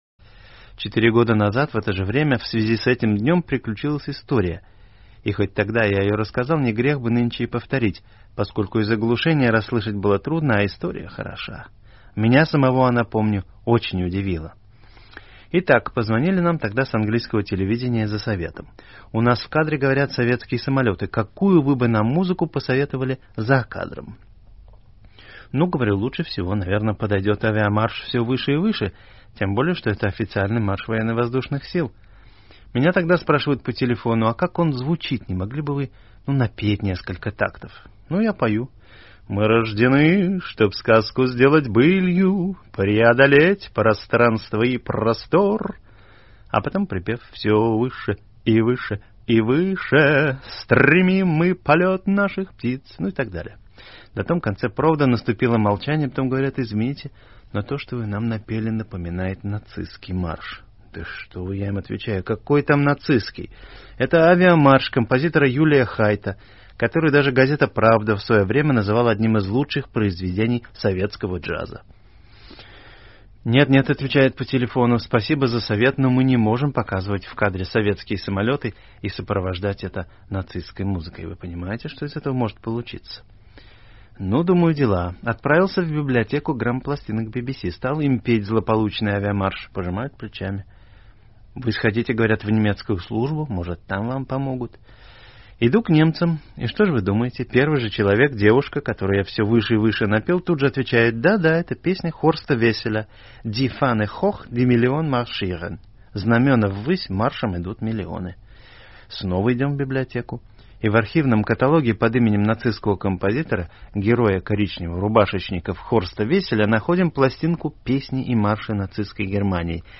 Звук в 1987 году был уже чудесный, поэтому — раз уж «слово в слово» — именно эту вторую попытку мы сейчас и послушаем полностью: